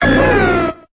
Cri de Celebi dans Pokémon Épée et Bouclier.